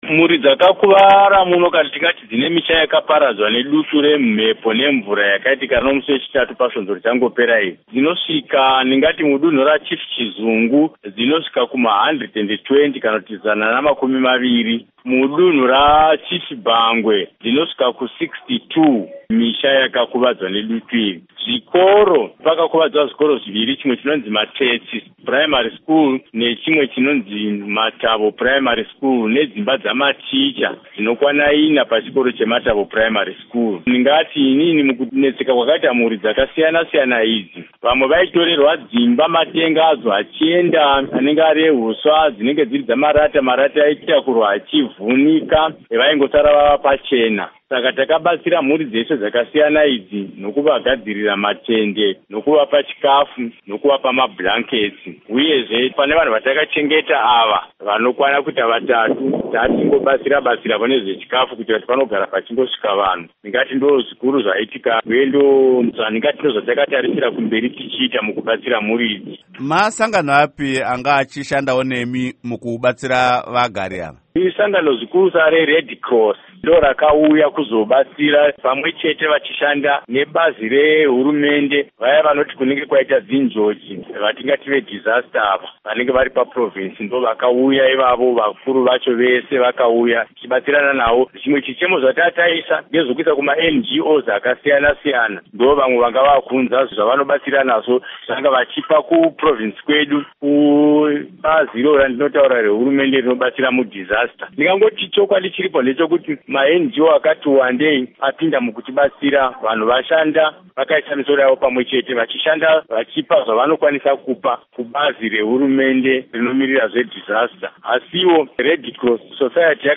Hurukuro naVaJoram Gumbo